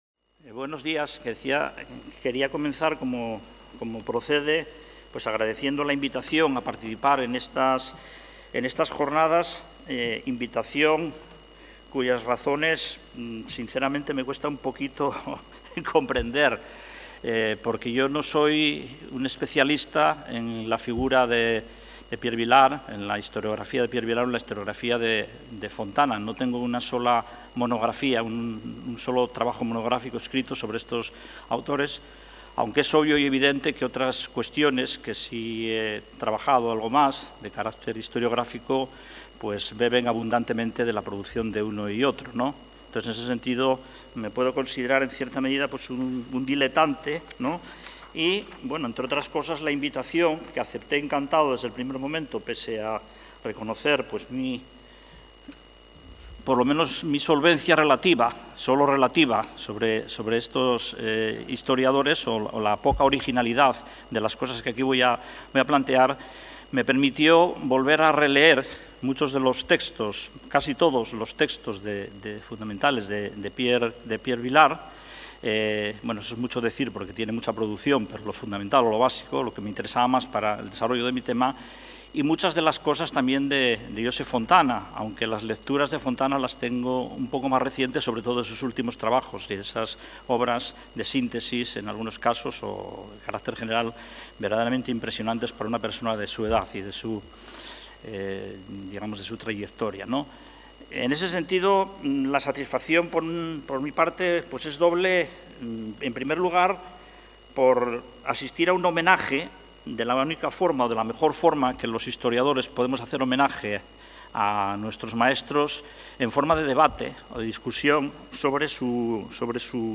Ponència